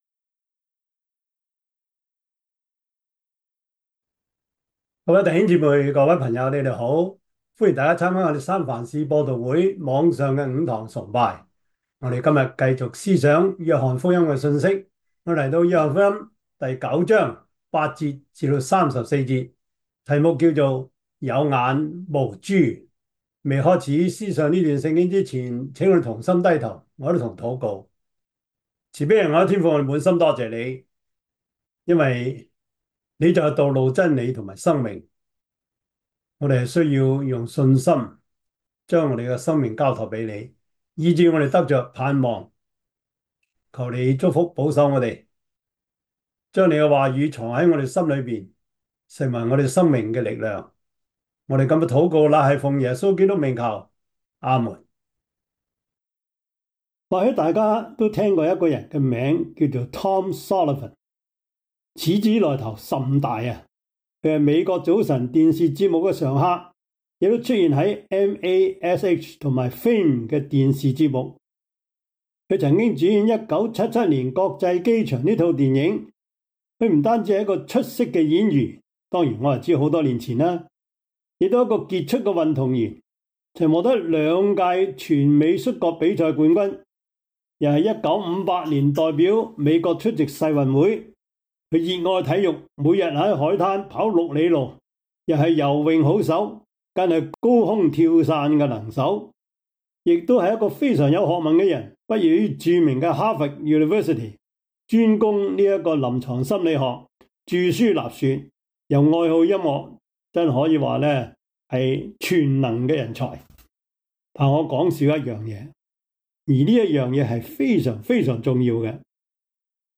約翰福音 9:8-34 Service Type: 主日崇拜 約翰福音 9:8-34 Chinese Union Version